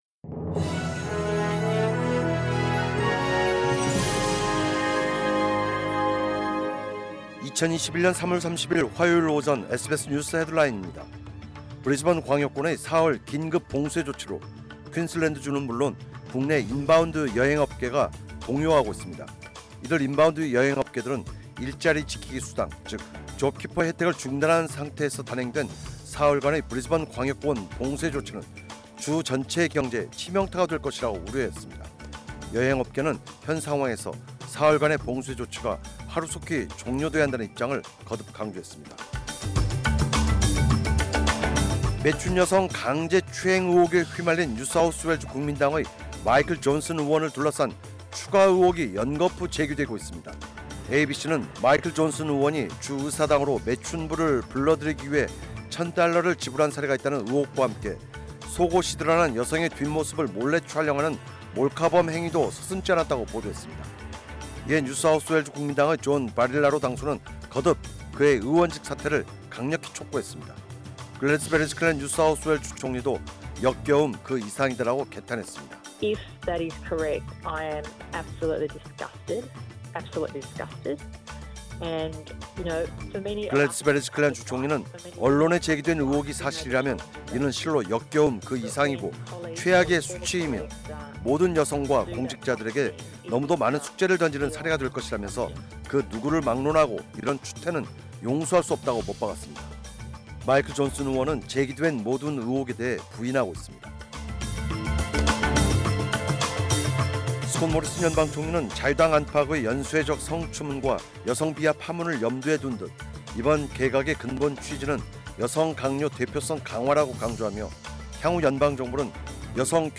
2021년 3월 30일 화요일 오전 SBS 뉴스 헤드라인입니다.